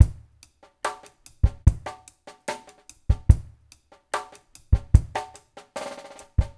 BOL DRUMS 3.wav